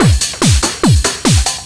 DS 144-BPM A3.wav